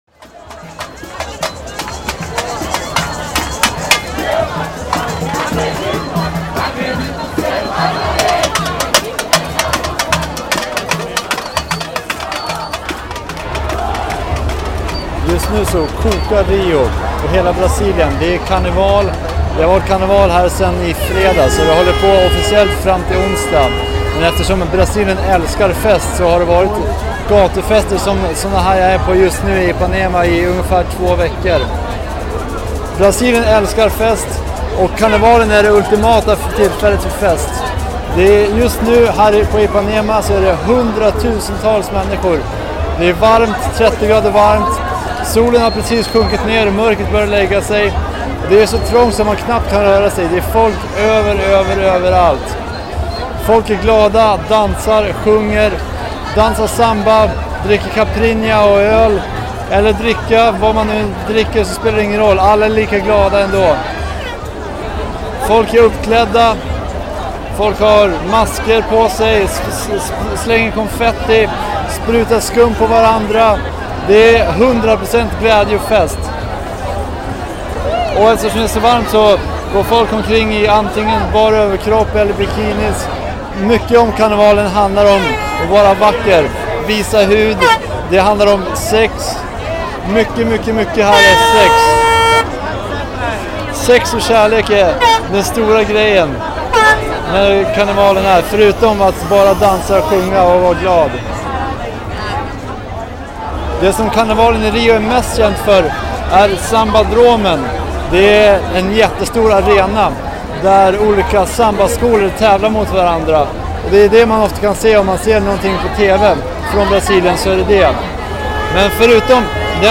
Här får du höra hur det kan vara på karnevalen.
Reportaget sändes i P3-programmet Transit.